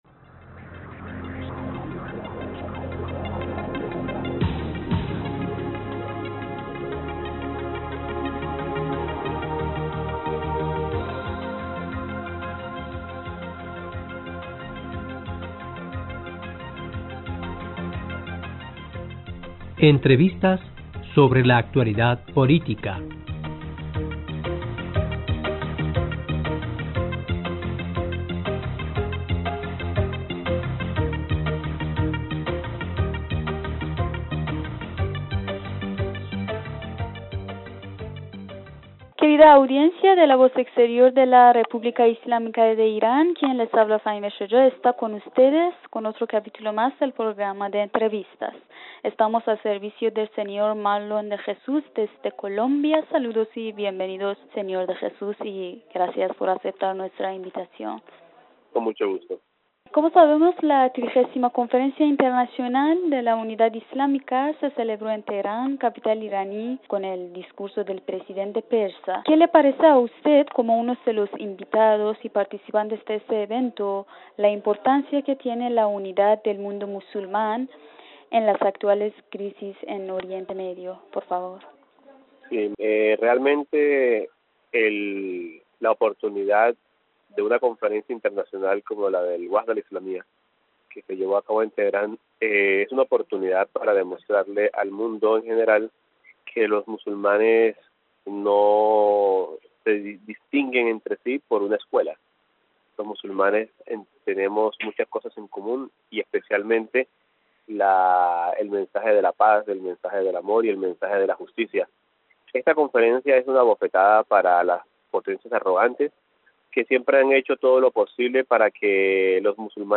esta con ustedes con otro capítulo más del programa de entrevistas